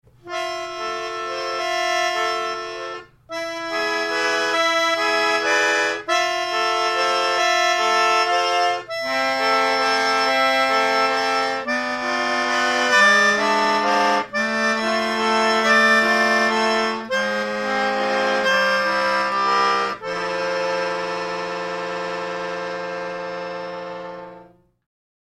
The spikesman, for melodica